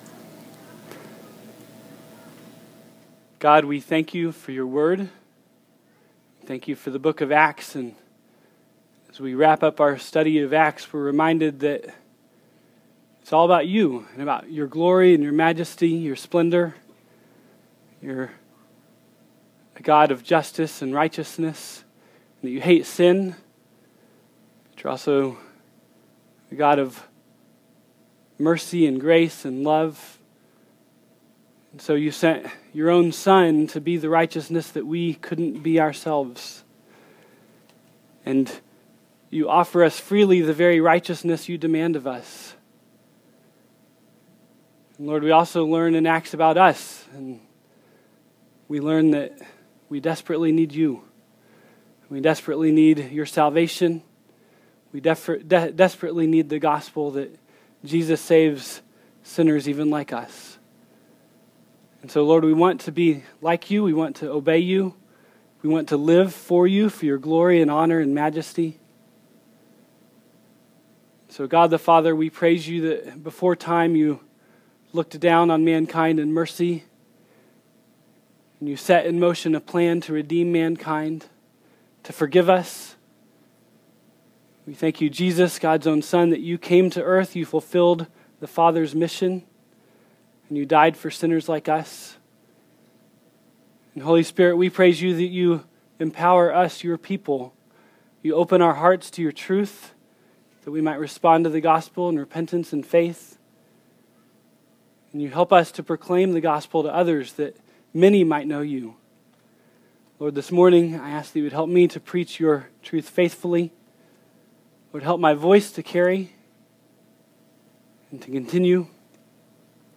2013 ( Sunday AM ) Bible Text